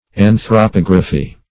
anthropography - definition of anthropography - synonyms, pronunciation, spelling from Free Dictionary
Anthropography \An`thro*pog"ra*phy\, n. [Gr.